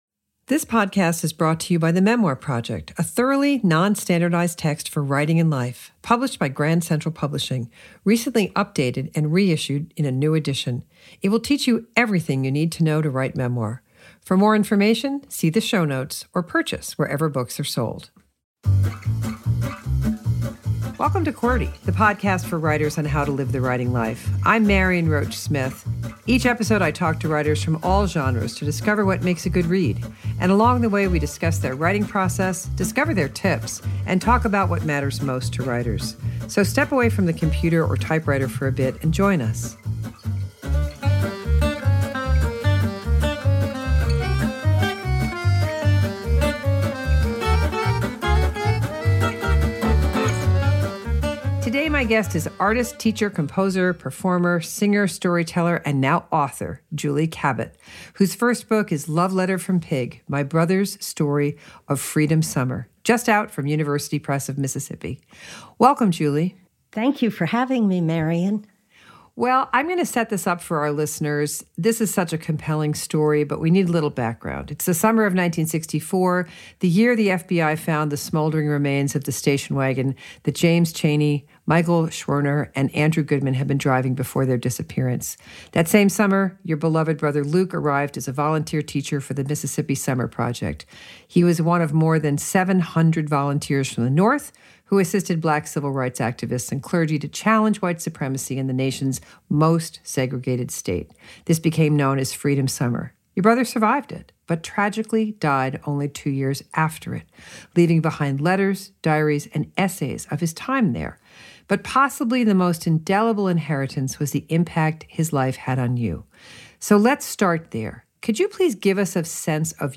interviews the best writers in all genres to discover their process.